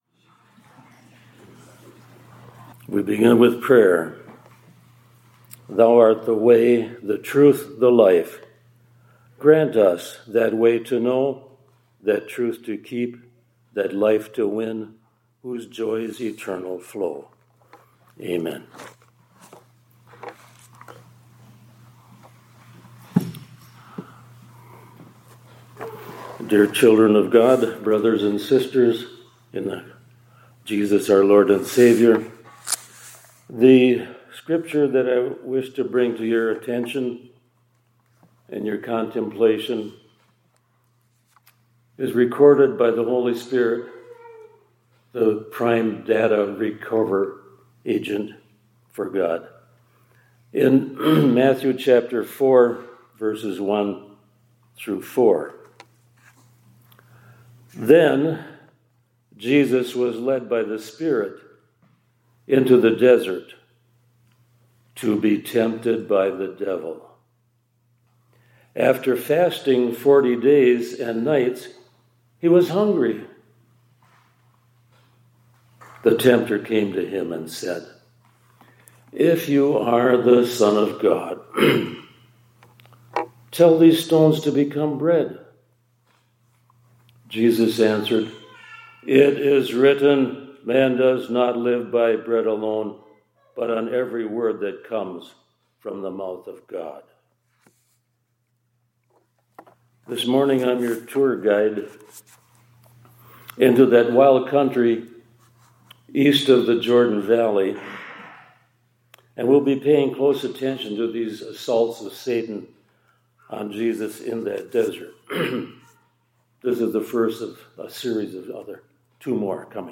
2026-03-05 ILC Chapel — Jesus’ Temptation Was Important — For Him and For Us